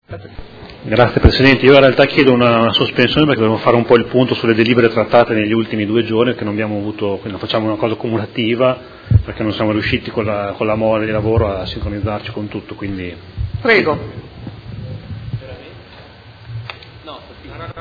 Seduta del 28/03/2019. Chiede sospensione